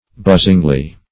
buzzingly - definition of buzzingly - synonyms, pronunciation, spelling from Free Dictionary
buzzingly - definition of buzzingly - synonyms, pronunciation, spelling from Free Dictionary Search Result for " buzzingly" : The Collaborative International Dictionary of English v.0.48: Buzzingly \Buzz"ing*ly\, adv. In a buzzing manner; with a buzzing sound.